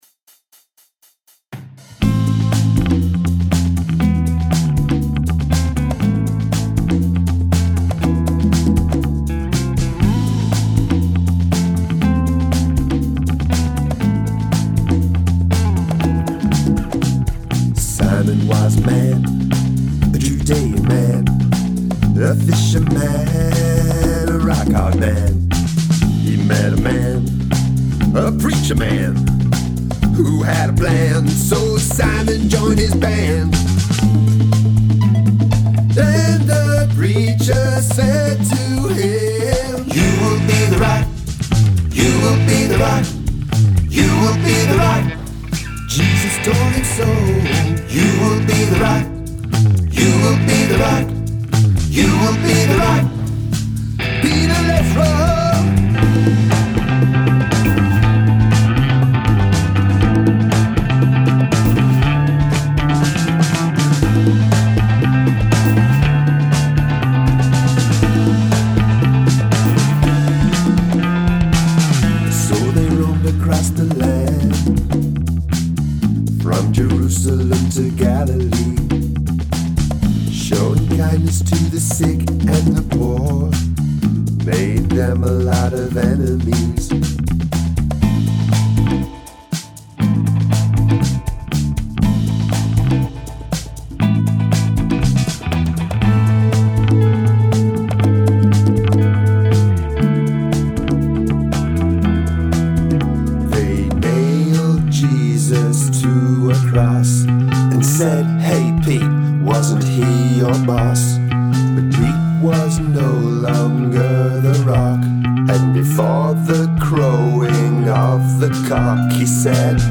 Great arrangements of all the different parts.